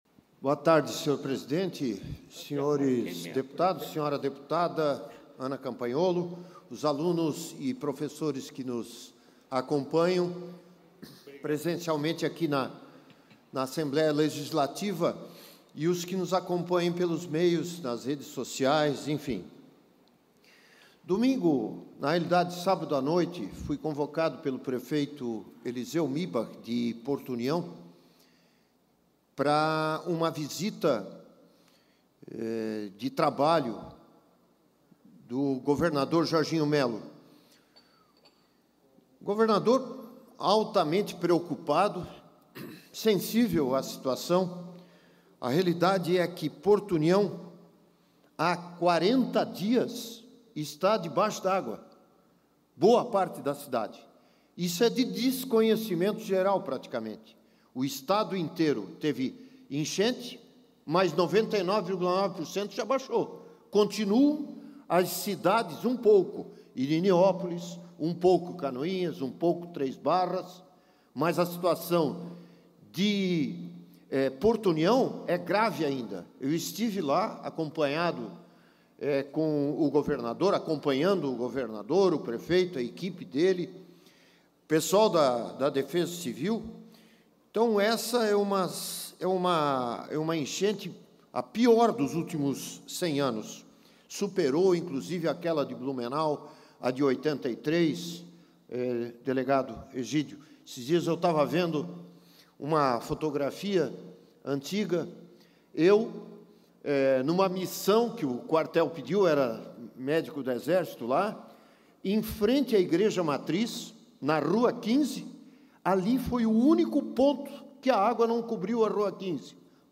Pronunciamentos da sessão ordinária desta terça-feira (14)
Confira os pronunciamentos dos deputados na sessão ordinária desta terça-feira (14):
- Dr. Vicente Caropreso (PSDB);